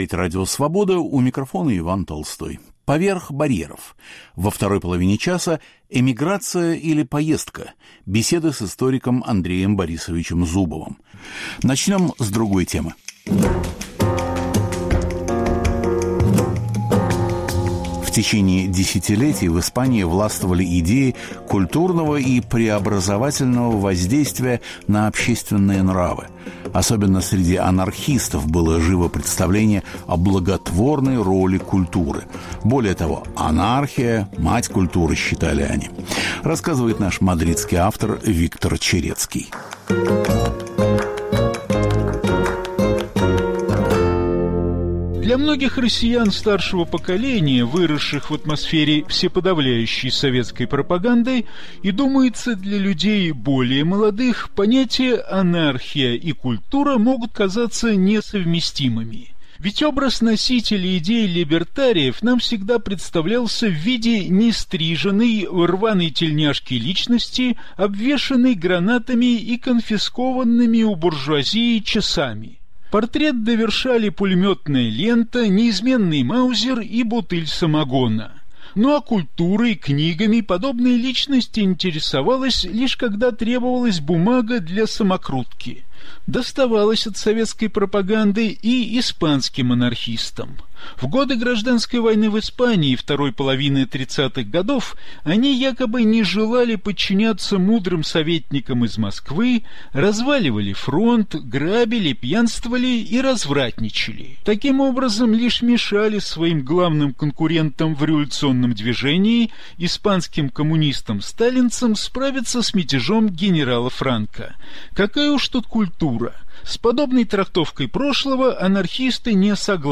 Во 2-й части - Эмиграция или поездка? Беседа с историком Андреем Зубовым.